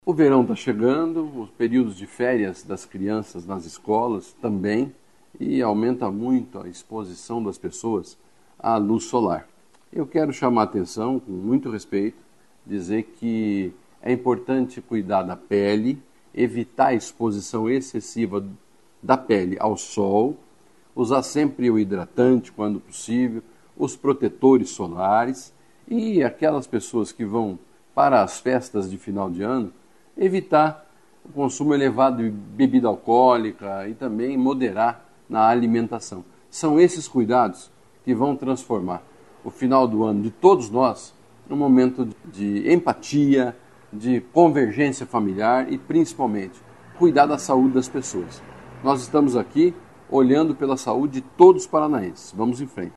Sonora do secretário da Saúde, Beto Preto, sobre cuidados com a pele, alimentação e hidratação no verão e nas festas de fim de ano